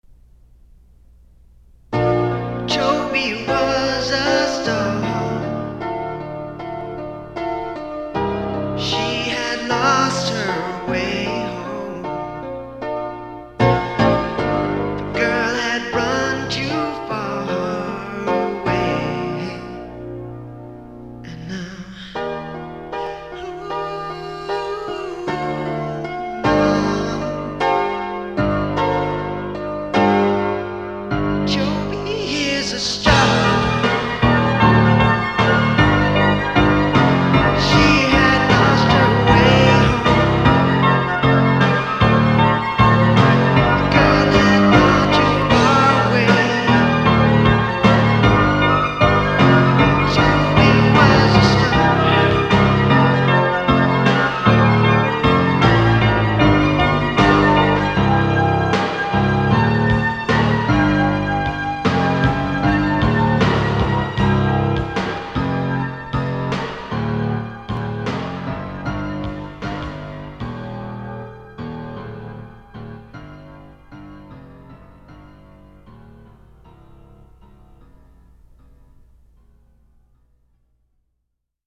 half-baked rock/noise/folk/pop